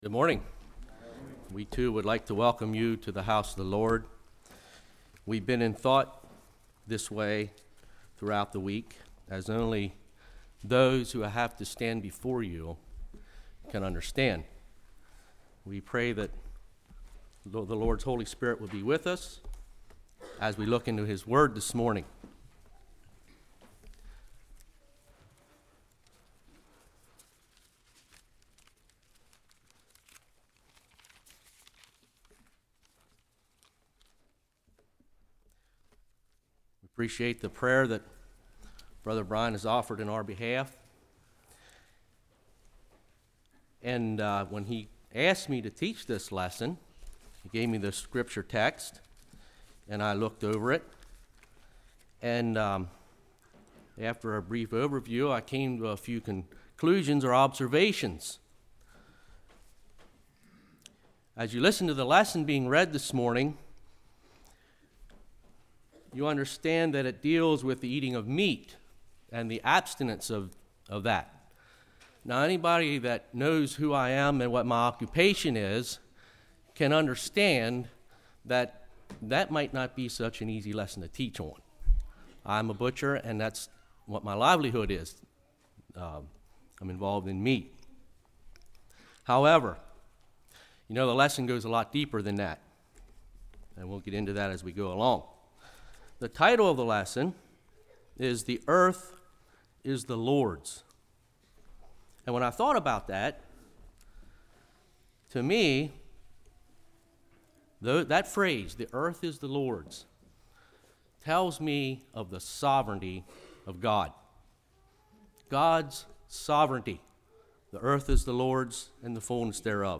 Series: Spring Revival 2011 Passage: 1 Corinthians 10:15-33 Service Type: Sunday School